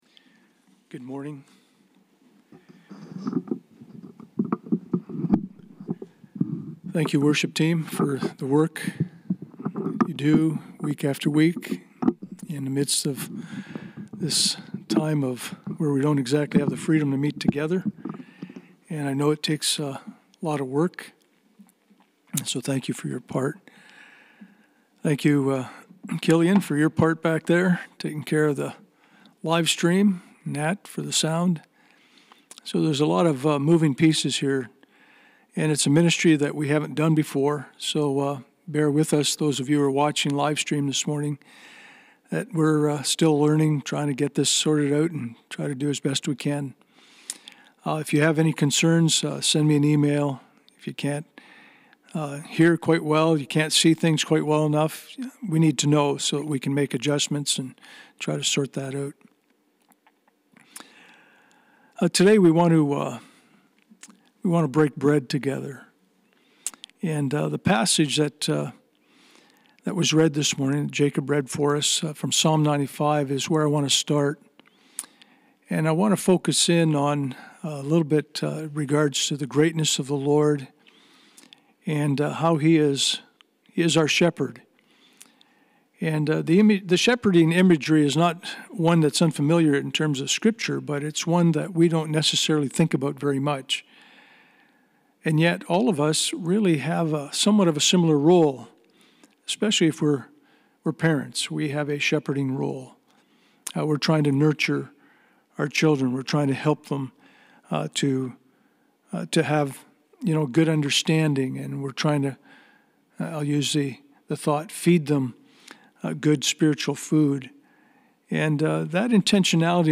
Psalm 95:1-7 Service Type: Sunday Morning « What Is The Wrath Of God That Is Being Revealed